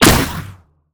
sci-fi_weapon_plasma_pistol_01.wav